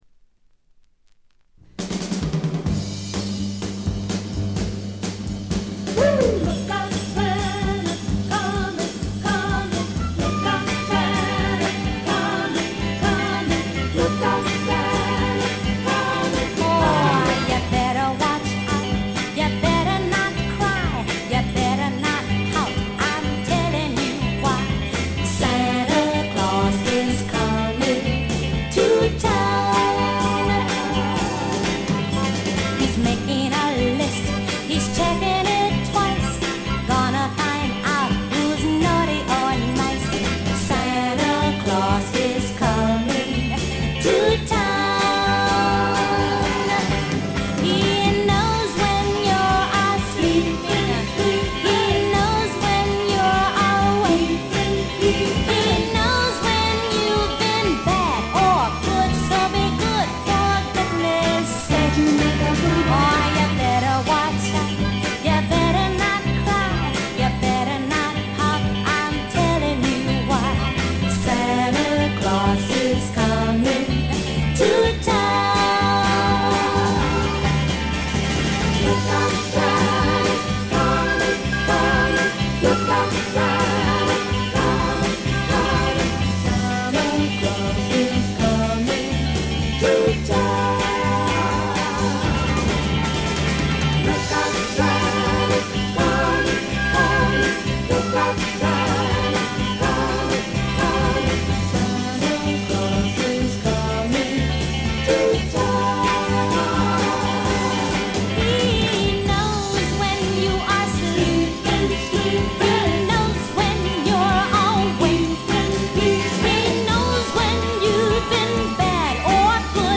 ガールポップ基本。
試聴曲は現品からの取り込み音源です。